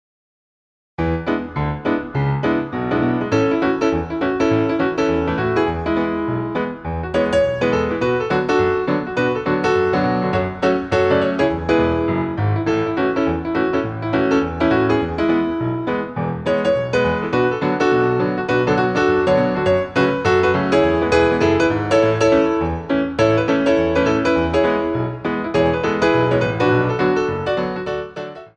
BATTEMENT GLISSÉ I